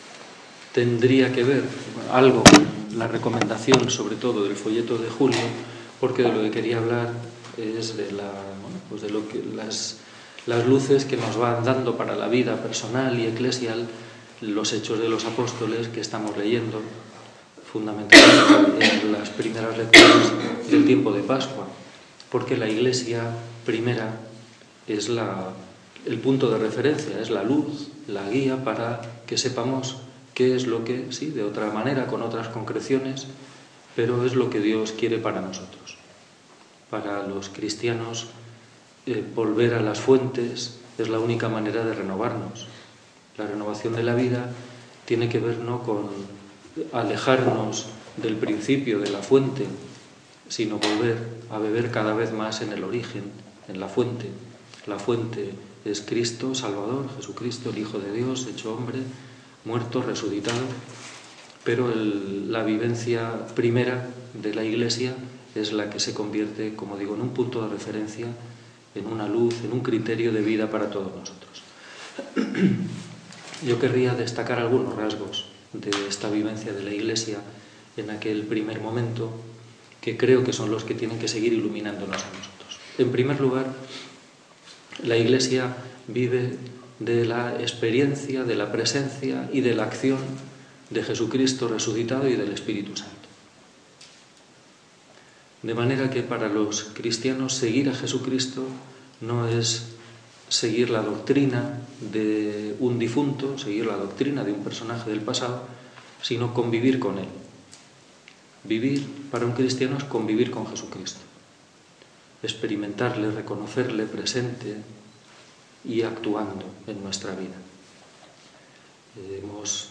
Retiro de Pascua